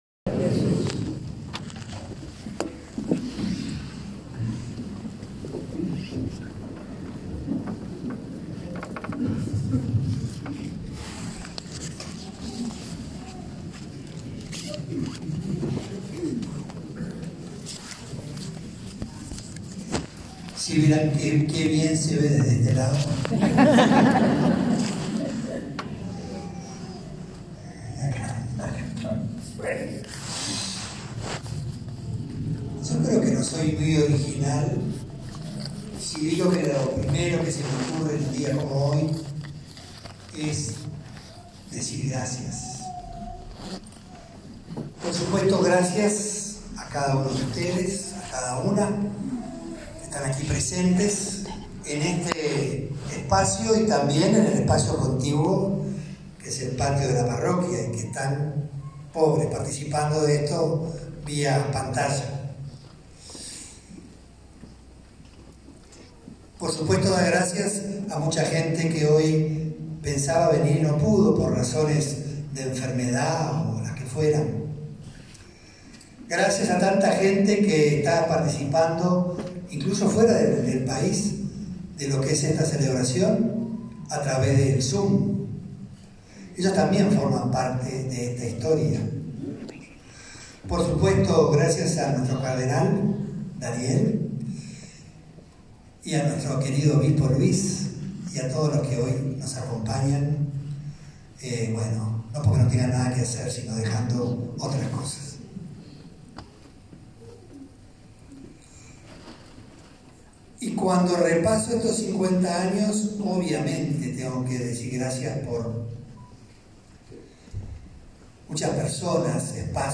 Compartimos el video-homenaje así como la homilía
durante la celebración Eucarística del 16 de junio junto a decenas de amigos y feligreses de su comunidad parroquial